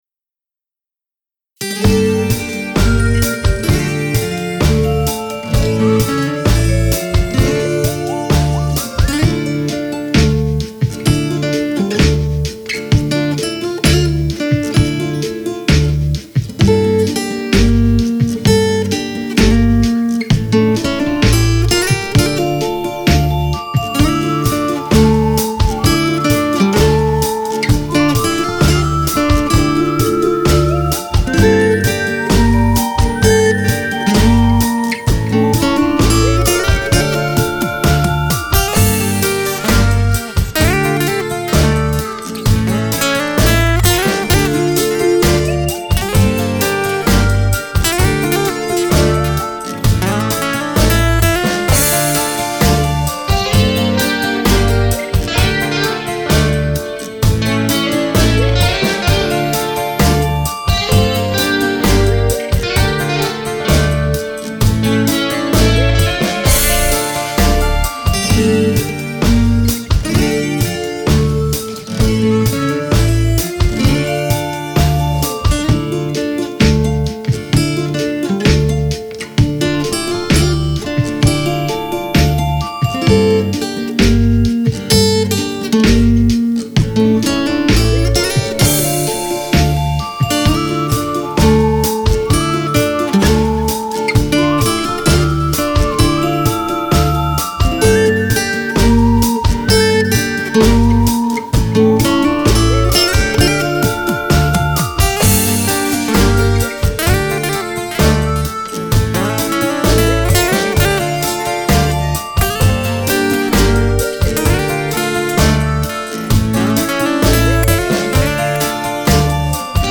инструментал